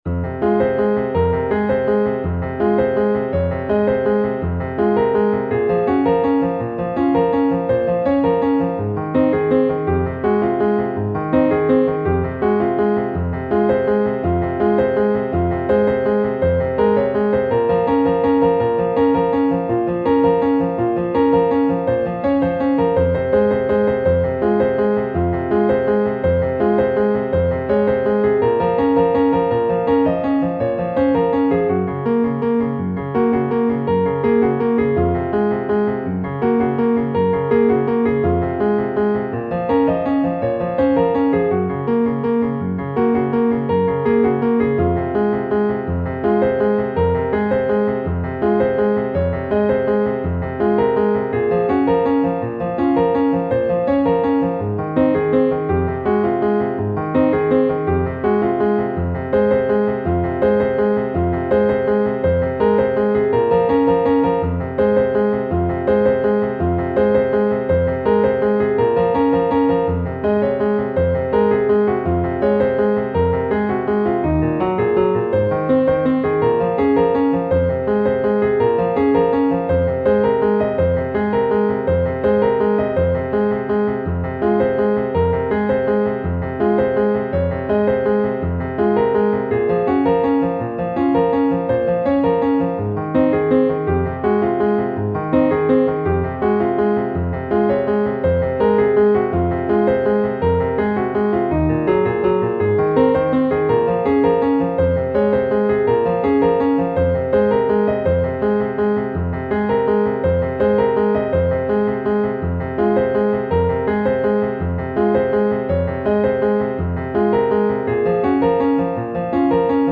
خواننده / آهنگساز : سرود انقلابی دهه فجر
نت پیانو